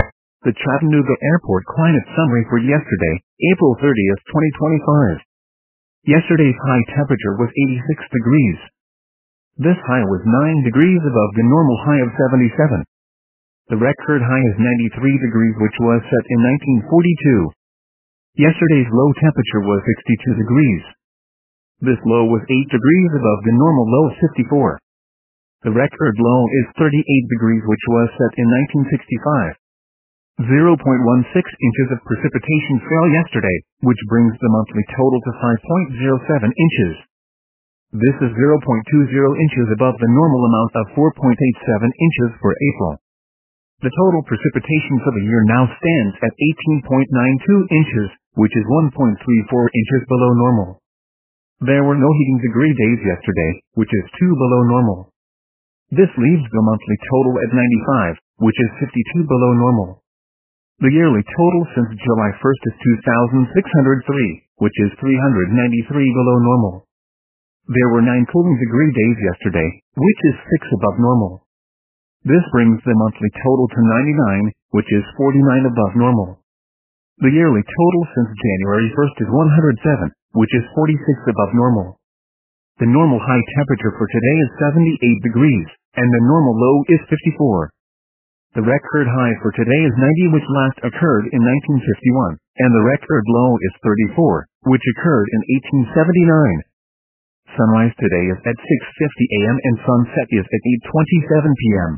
MRX Weather Radio Forecasts